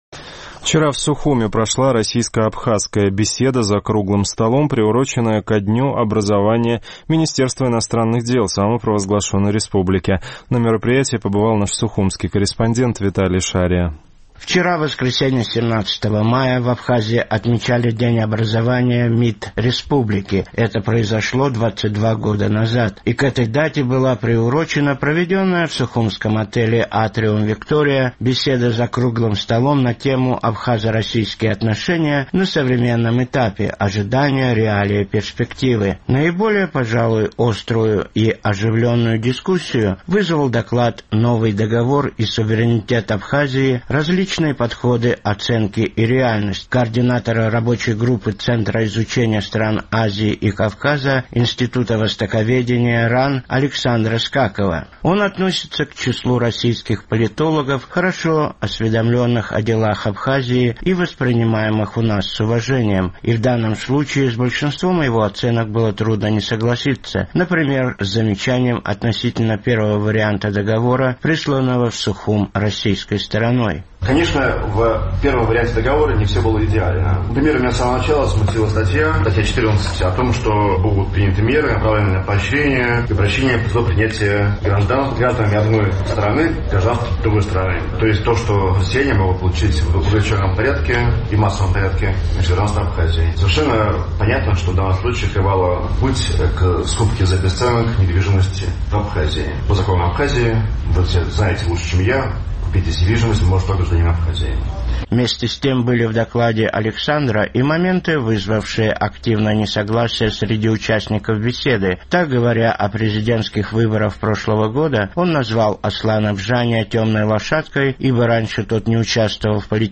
Ко дню образования МИД Абхазии была приурочена в сухумском отеле «Атриум-Виктория» беседа за «круглым столом».